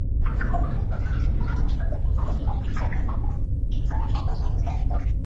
drip.ogg